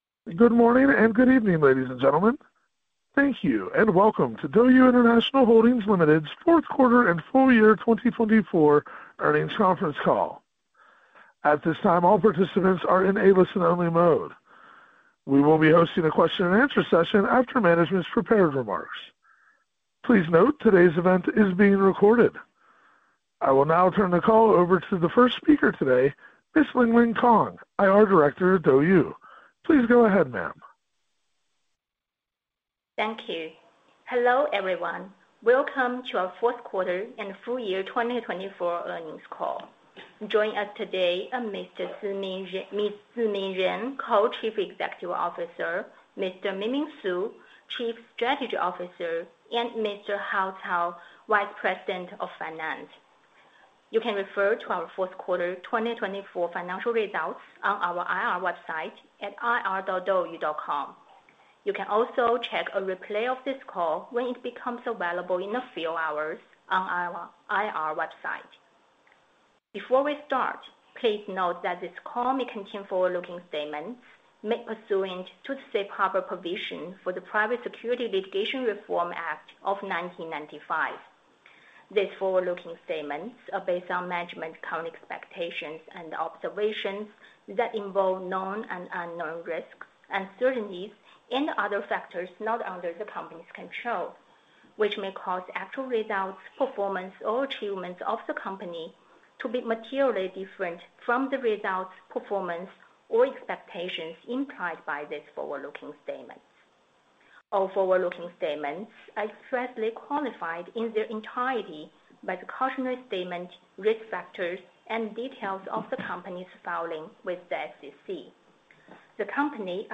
DouYu International Holdings Limited Fourth Quarter 2024 Earnings Conference Call